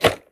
gear_rattle_weap_launcher_01.ogg